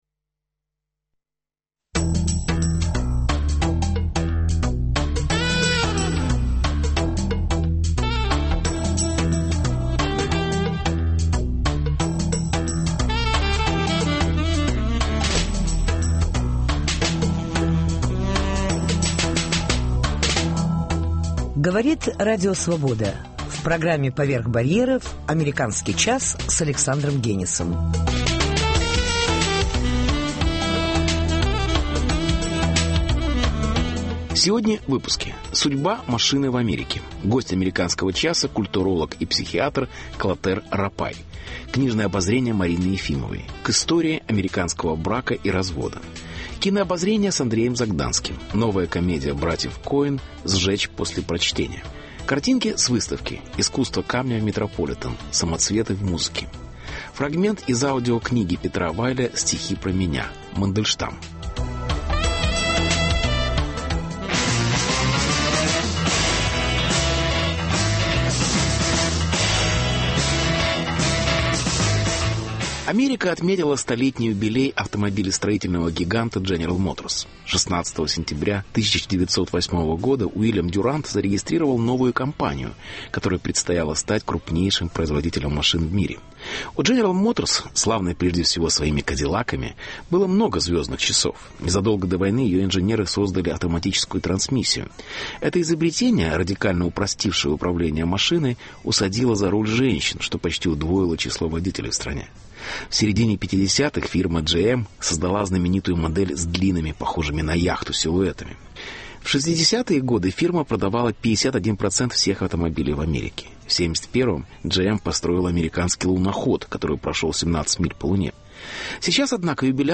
Интервью. Судьба машины в Америке.